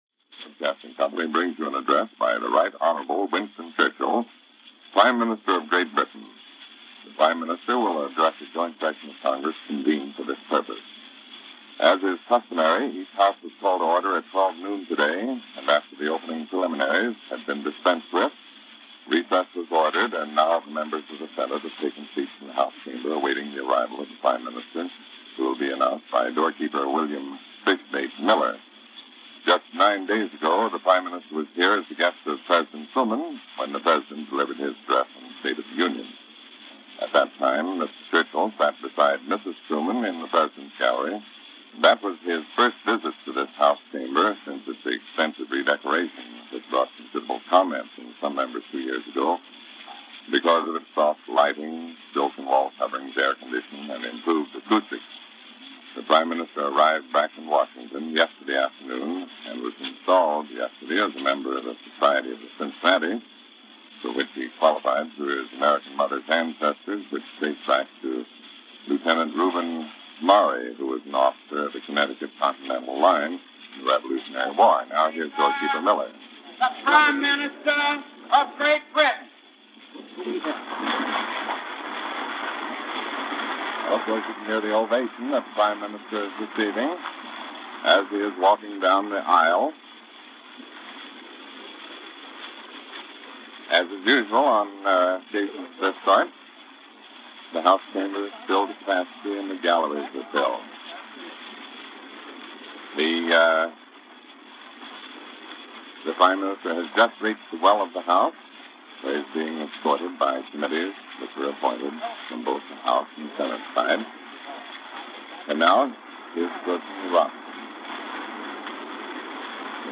Winston Churchill Addresses Congress - January 17, 1952 - Joint session of congress - carried by all networks.
The British prime minister addressed the houses of the U. S.congress jointly assembled.